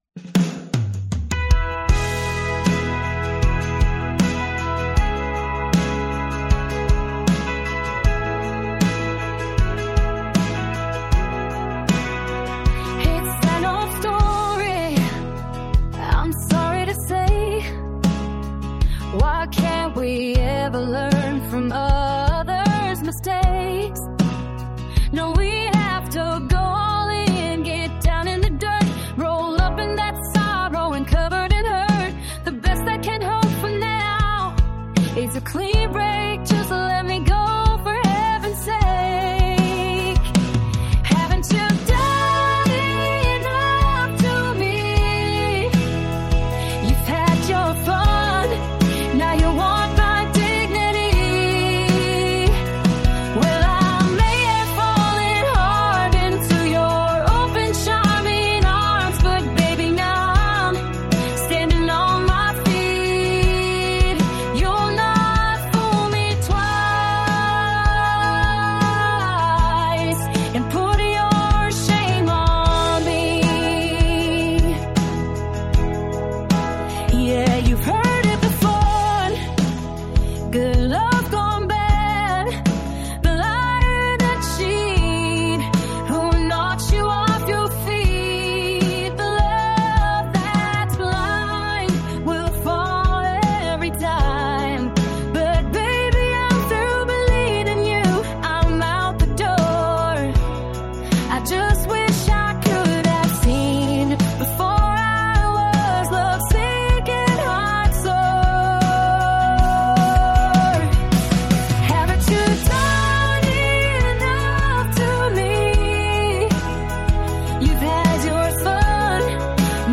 A country song i wrote and used an app to make it into a demo with music and vocal.